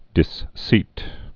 (dĭs-sēt)